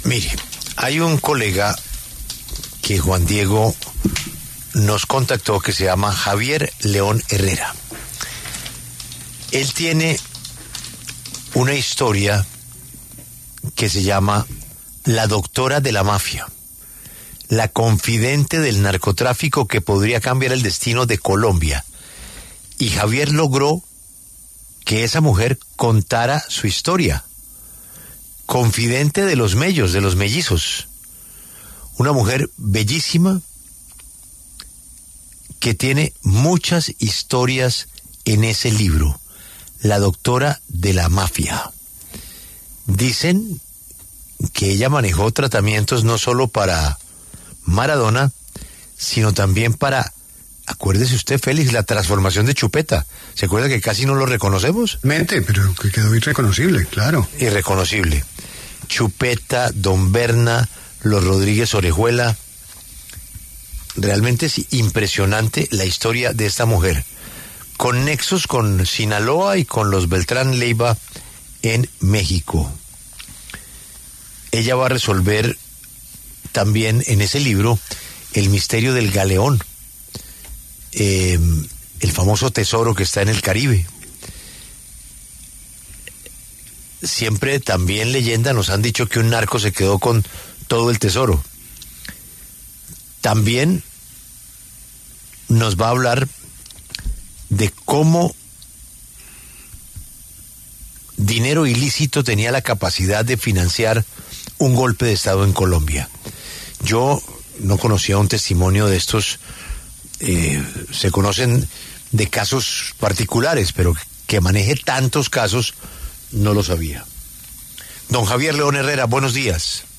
En conversación con La W